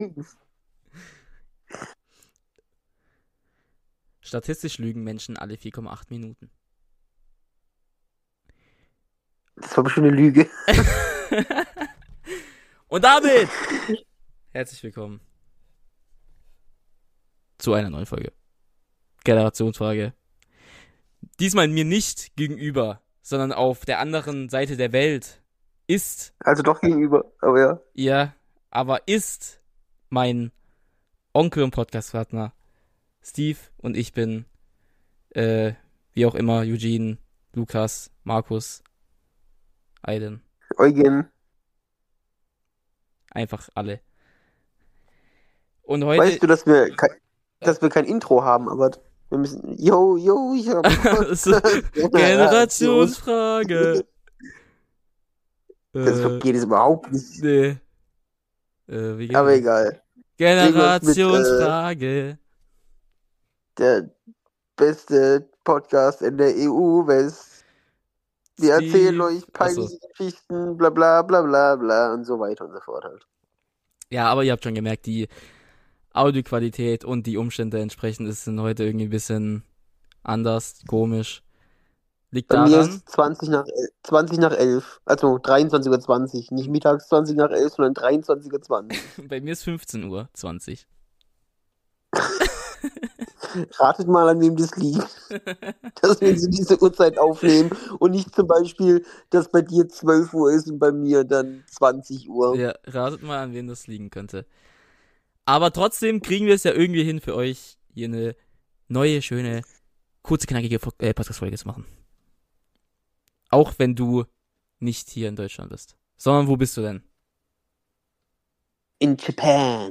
Hat irgendwie auch seinen vibe....